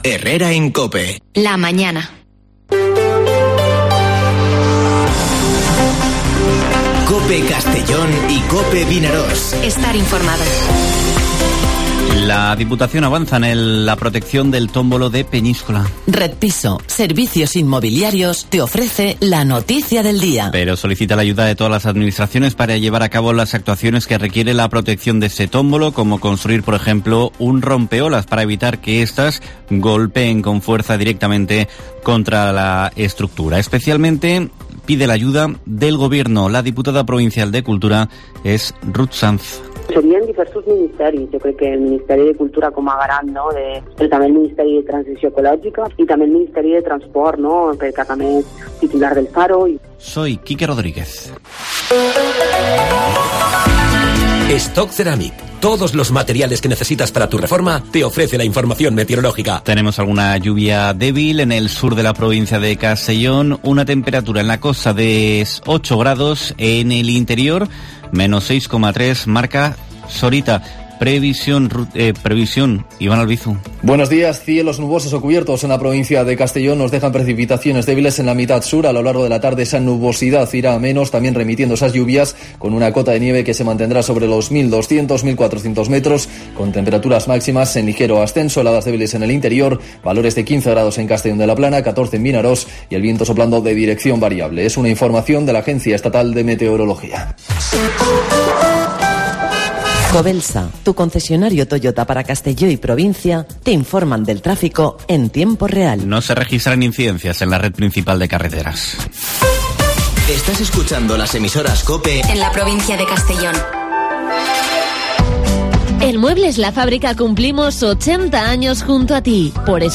Informativo Herrera en COPE en la provincia de Castellón (25/01/2022)